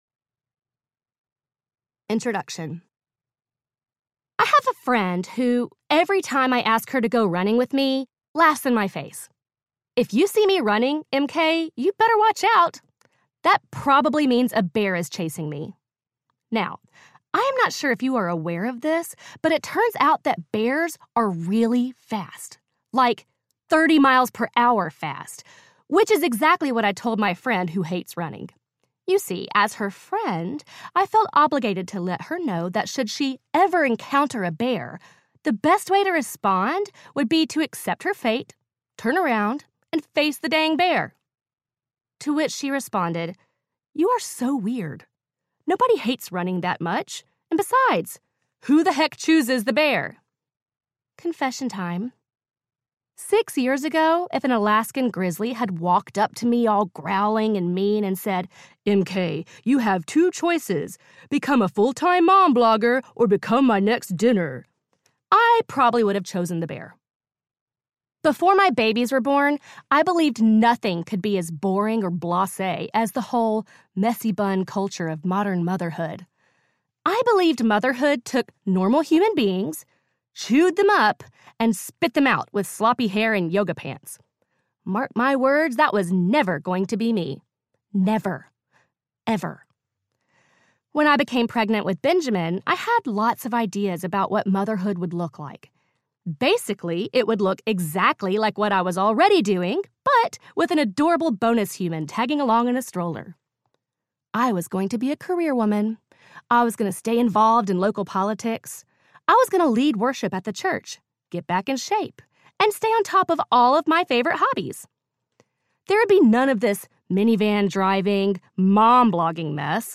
Mom Babble Audiobook